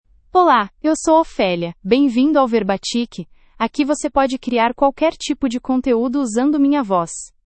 Ophelia — Female Portuguese (Brazil) AI Voice | TTS, Voice Cloning & Video | Verbatik AI
OpheliaFemale Portuguese AI voice
Ophelia is a female AI voice for Portuguese (Brazil).
Voice sample
Listen to Ophelia's female Portuguese voice.
Ophelia delivers clear pronunciation with authentic Brazil Portuguese intonation, making your content sound professionally produced.